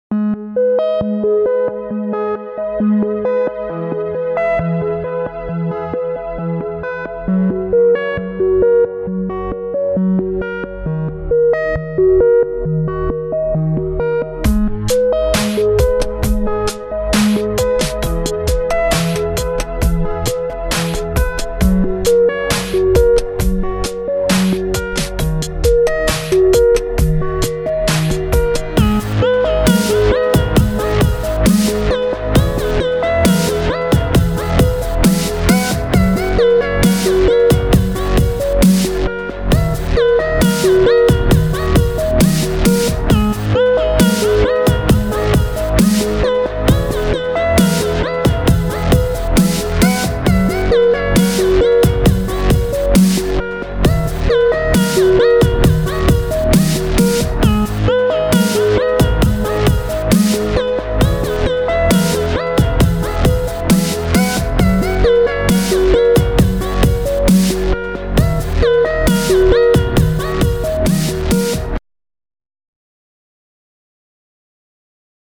• Качество: 320, Stereo
красивые
без слов
club
космические